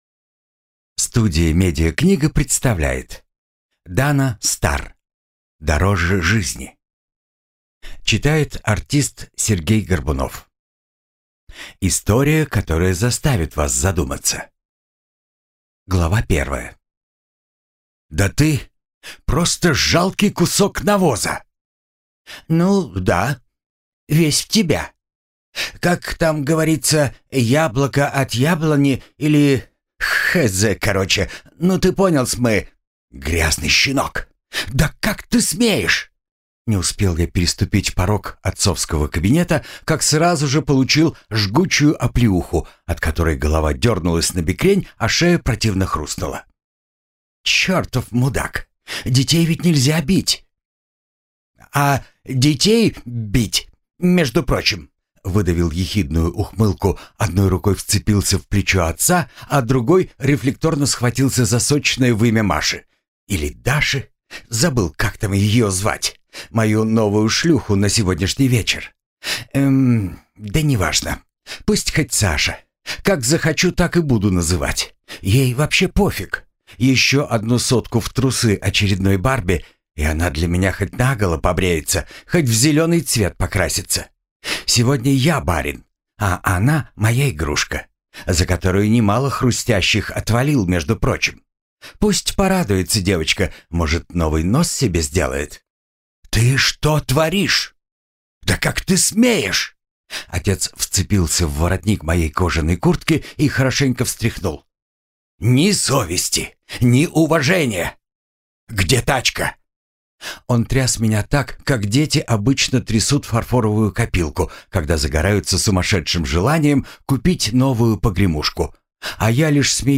Аудиокнига Дороже жизни | Библиотека аудиокниг